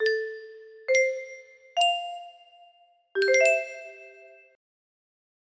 Surpresa! music box melody